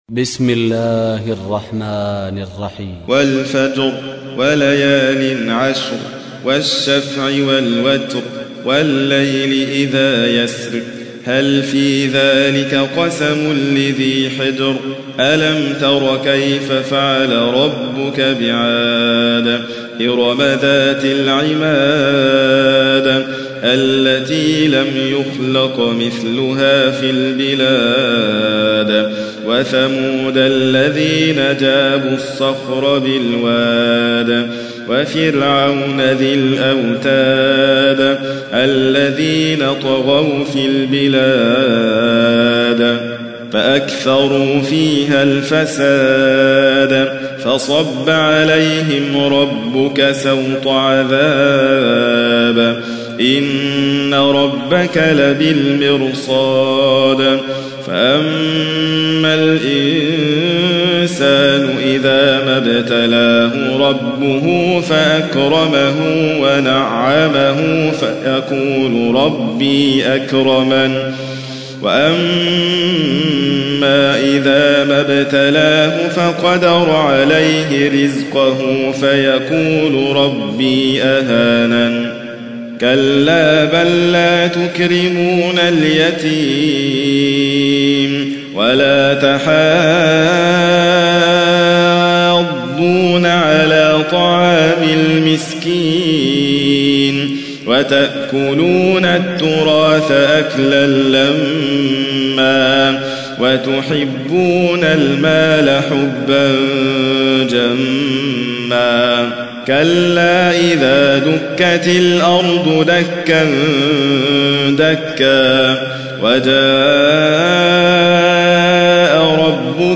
تحميل : 89. سورة الفجر / القارئ حاتم فريد الواعر / القرآن الكريم / موقع يا حسين